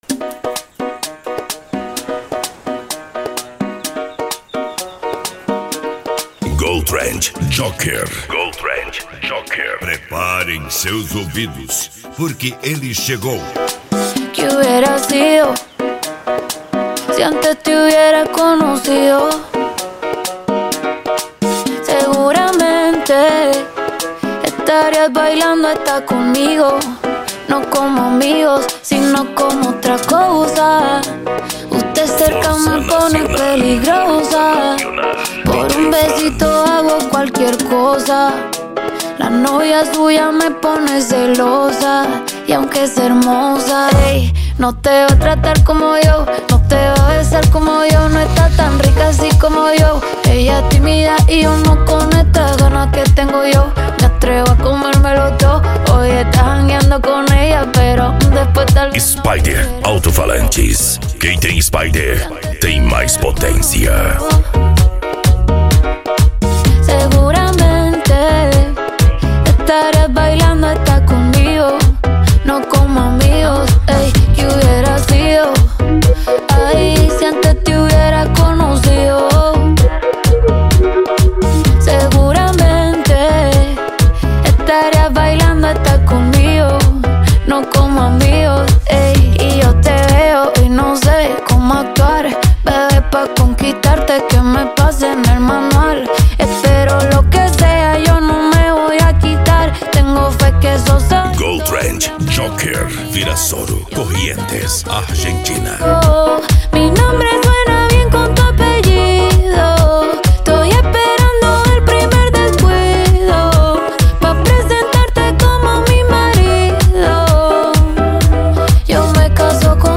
Arrocha
Funk
Remix